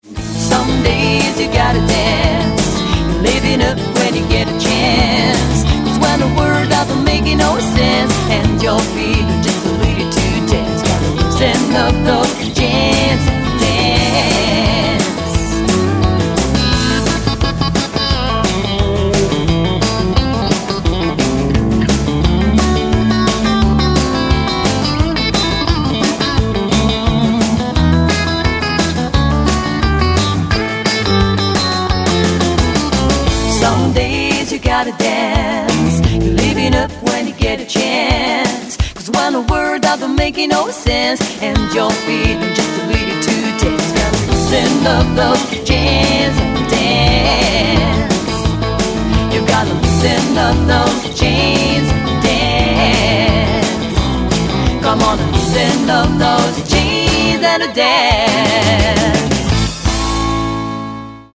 Chant, Harmonies
Basse, Programmation Batterie, Harmonies
Guitare Acoustique & Electrique, Programmation Batterie
Guitare Additionnelle